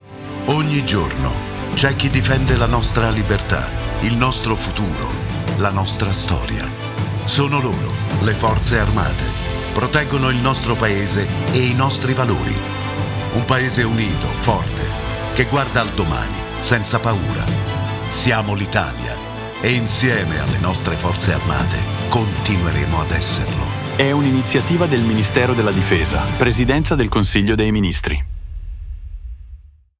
Lo spot televisivo